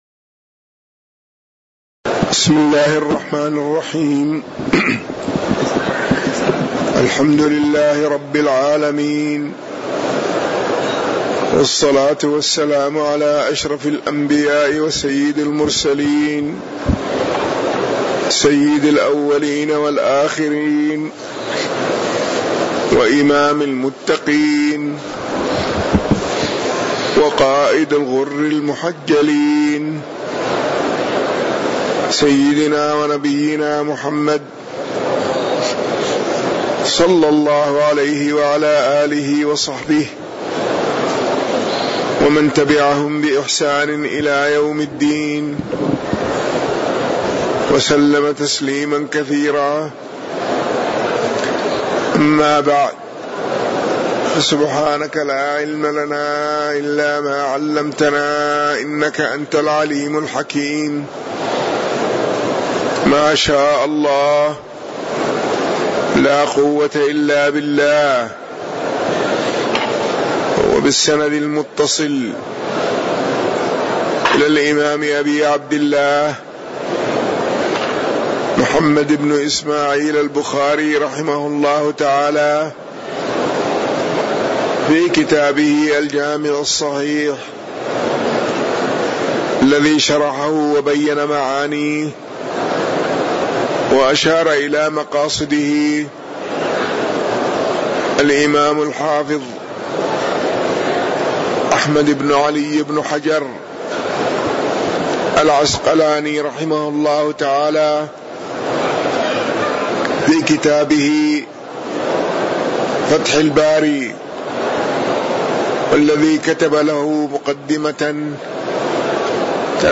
تاريخ النشر ٢٩ رجب ١٤٣٩ هـ المكان: المسجد النبوي الشيخ